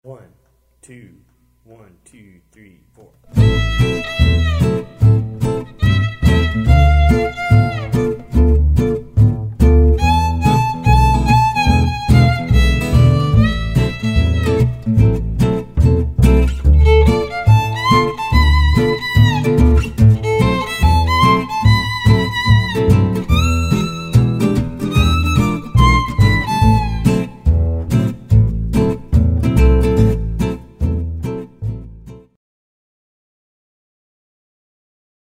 -Slides y notas de adorno: